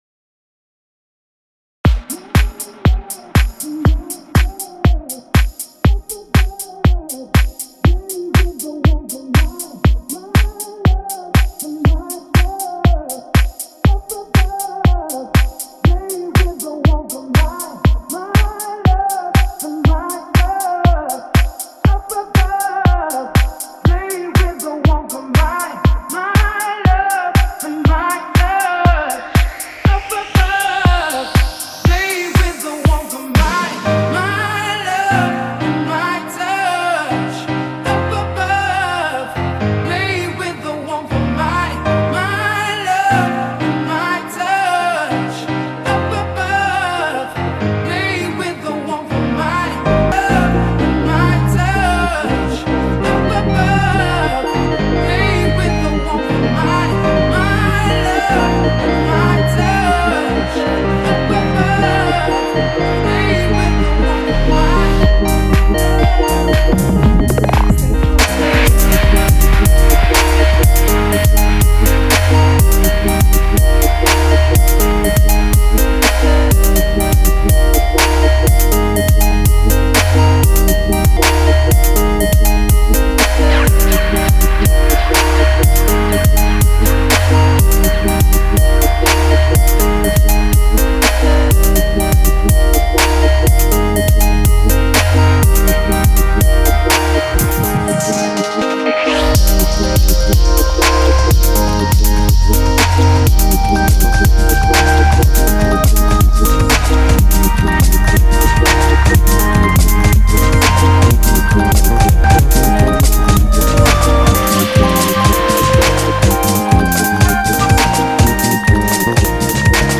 I gave myself five minutes to record a house mix.
This was recorded live with no re-edits.